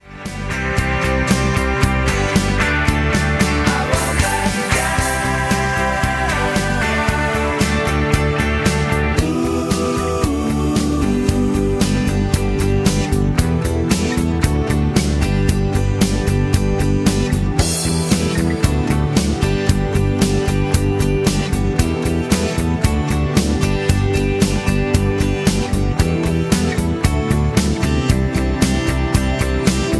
MPEG 1 Layer 3 (Stereo)
Backing track Karaoke
Pop, Rock, 1980s